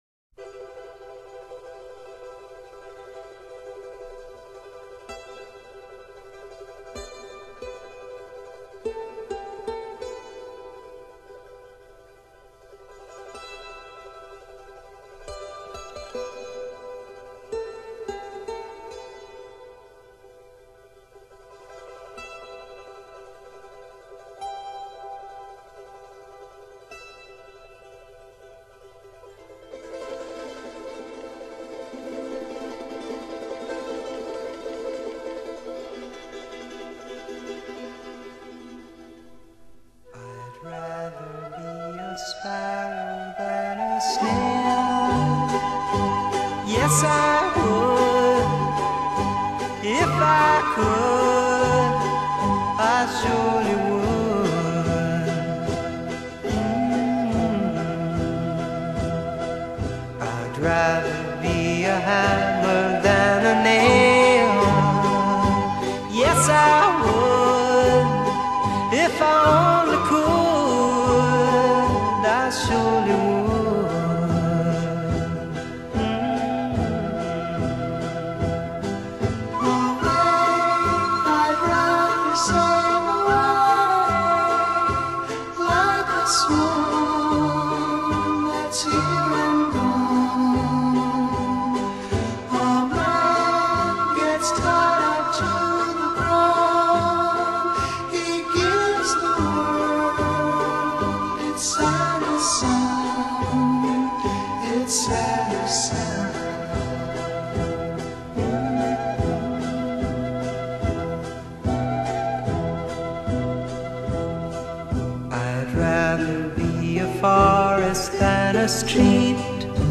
Genre: Folk, Rock